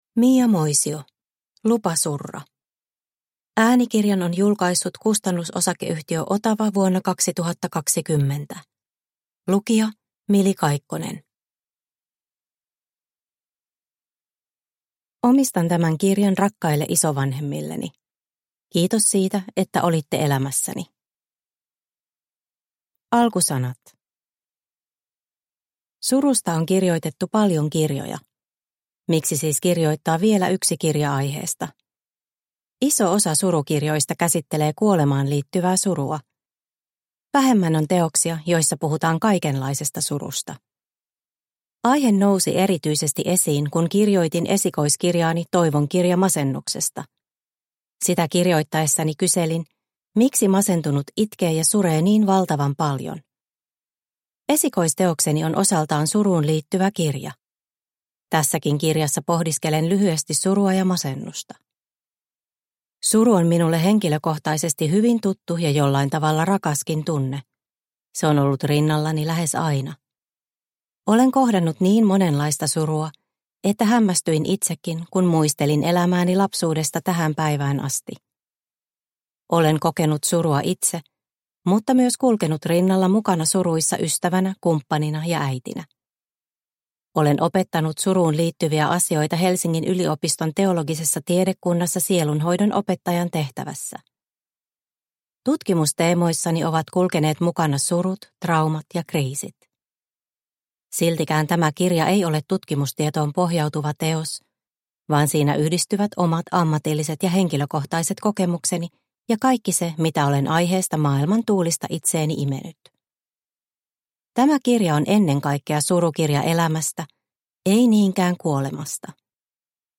Lupa surra – Ljudbok – Laddas ner